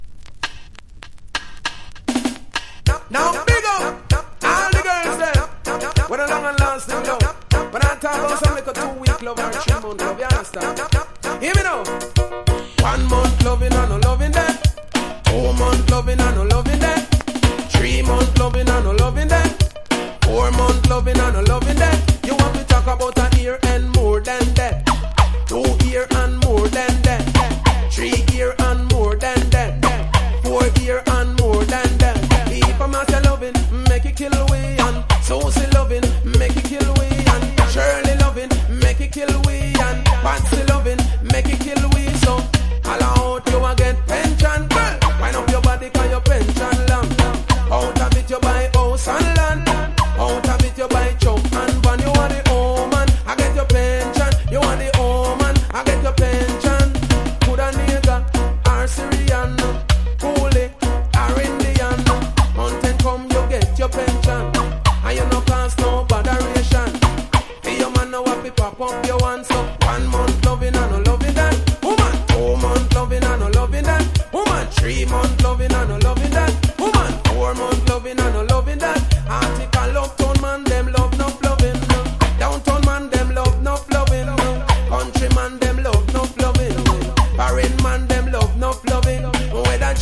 • REGGAE-SKA
# DANCE HALL